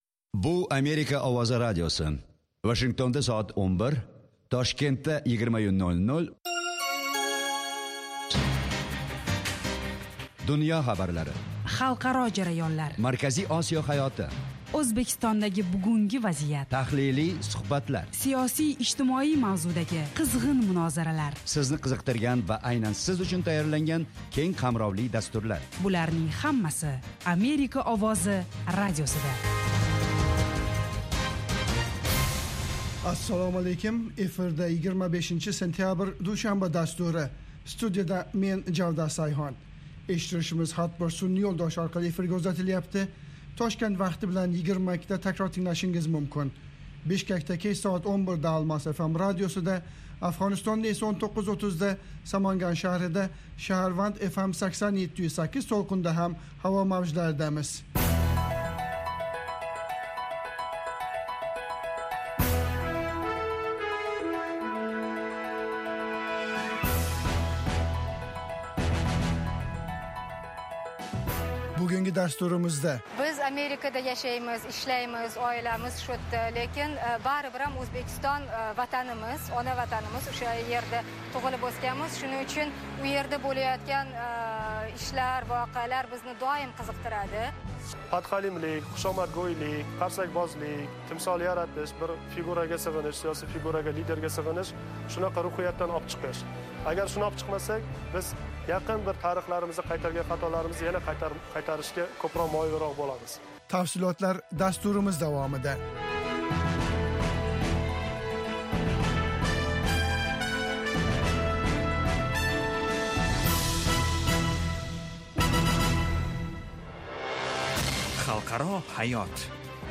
Toshkent vaqti bilan har kuni 20:00 da efirga uzatiladigan 30 daqiqali radio dastur kunning dolzarb mavzularini yoritadi. Xalqaro hayot, O'zbekiston va butun Markaziy Osiyodagi muhim o'zgarishlarni, shuningdek, AQSh bilan aloqalarni tahlil qiladi.